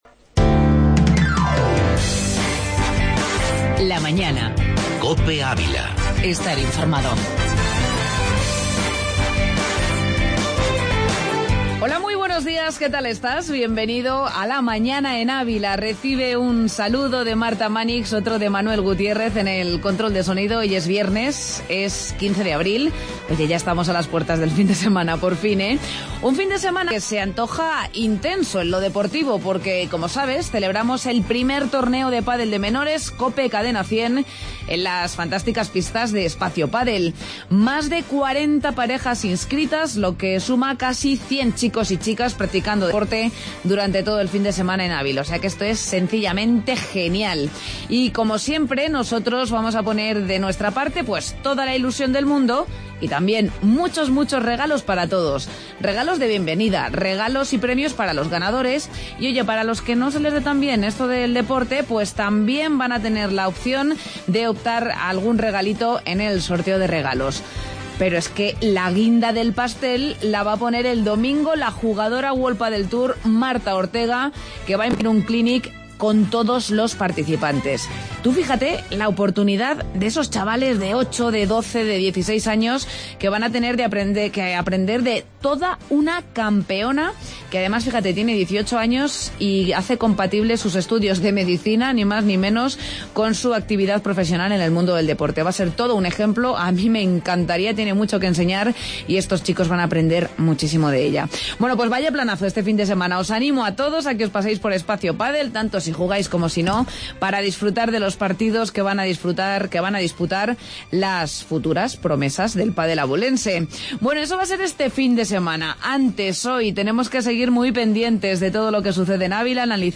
AUDIO: Entrevista Vespávilada y El Folio en Blanco